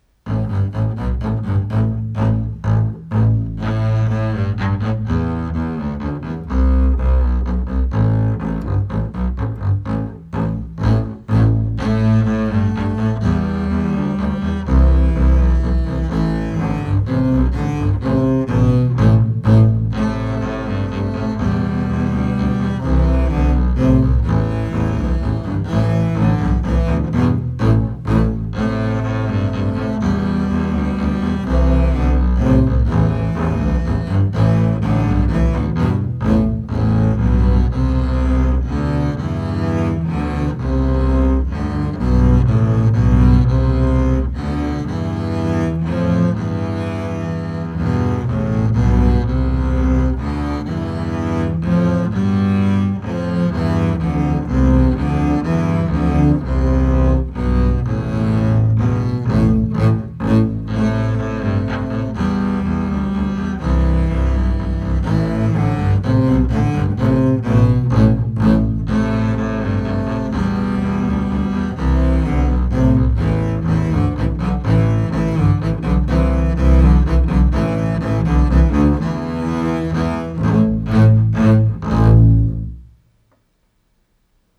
Voicing: 3 Bass